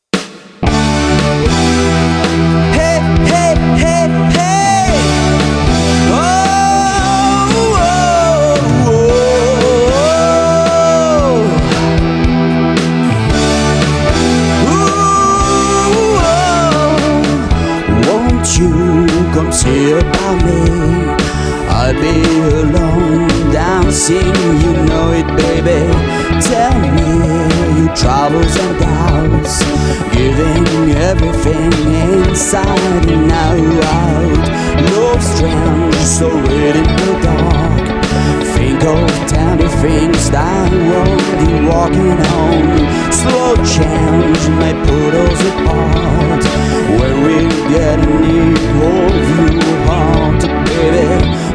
Studio Côtier, Frontignan, France.
Guitare
Chant, Choeurs
Basse